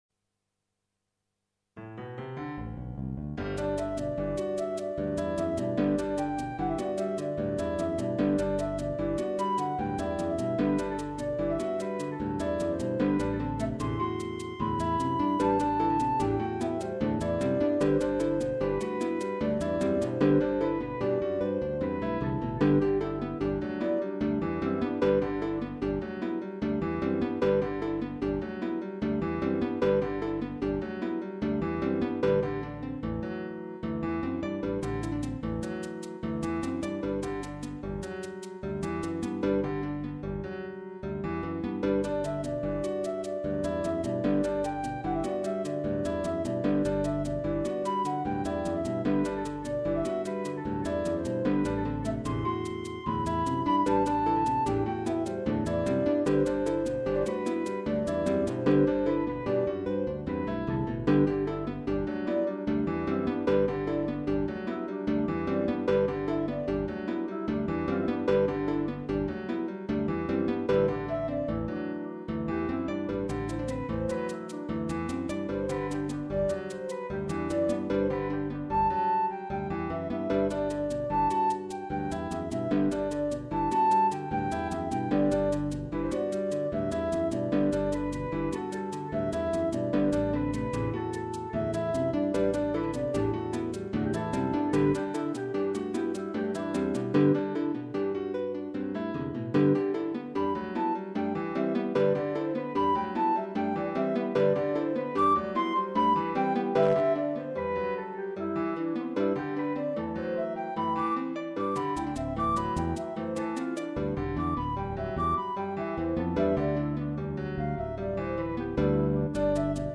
Type: Electronically Generated Performers